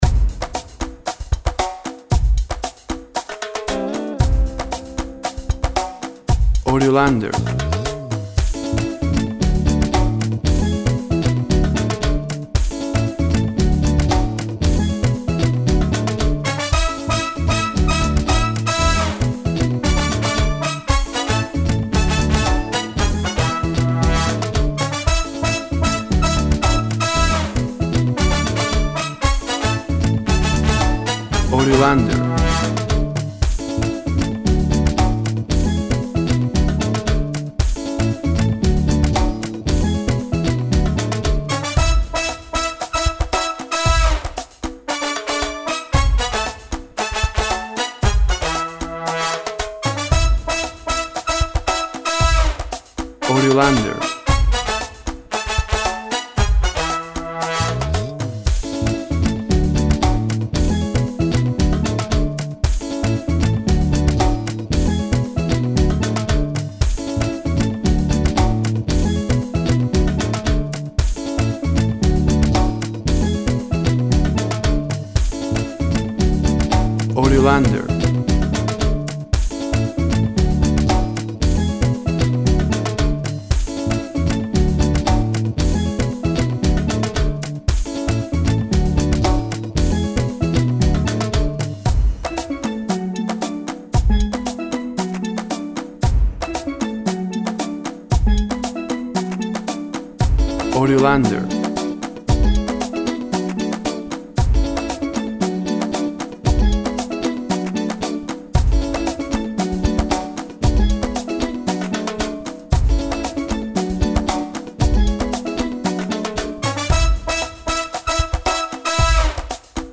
Tempo (BPM) 115